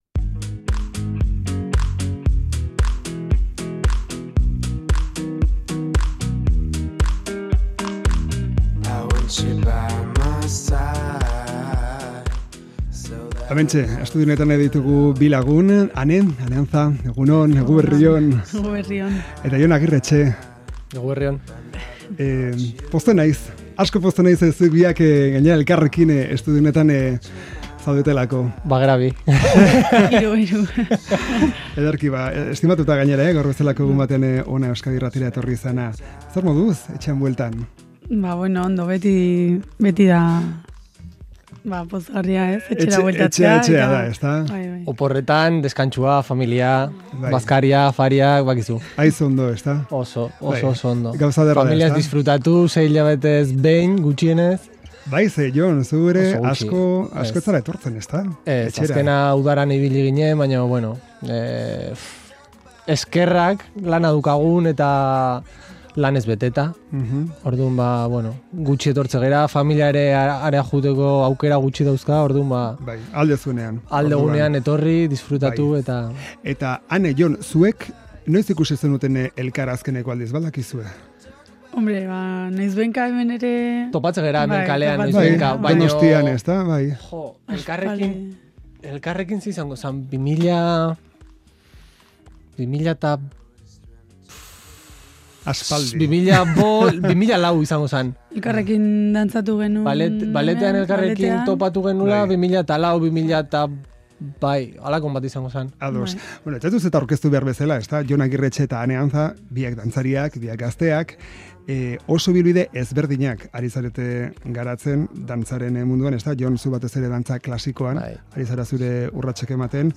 Bide batez, gure estudiora gonbidatu ditugu.